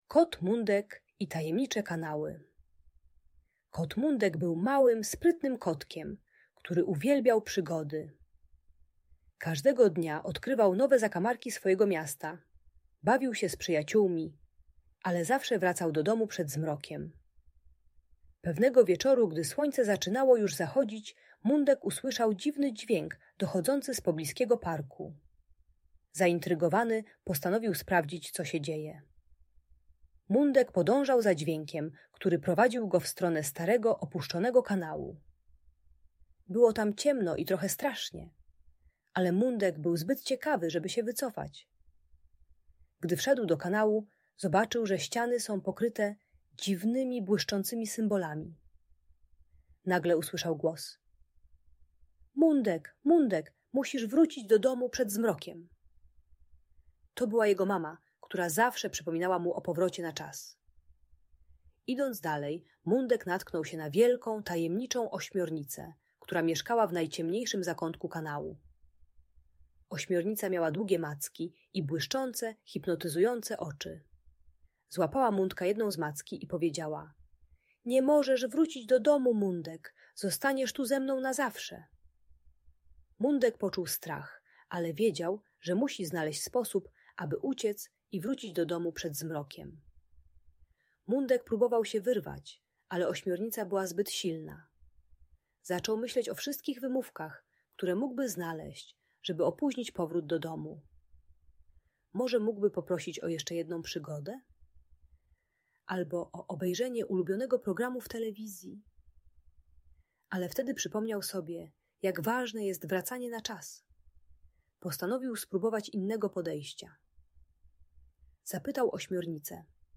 Bajka pomagająca zasnąć dla 4 latka i starszych przedszkolaków (4-6 lat). Ta uspokajająca audiobajka na dobranoc uczy dziecko, że warto wracać do domu na czas i dotrzymywać obietnic.